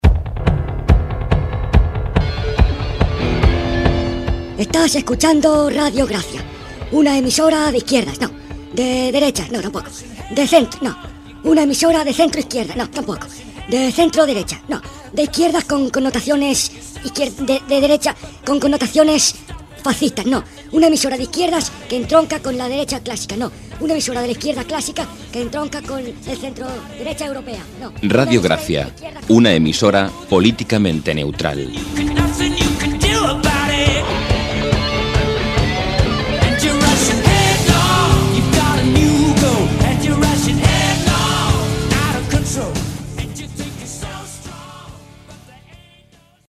Indicatiu de l'emissora "Ràdio Gràcia una emisora políticamente neutral".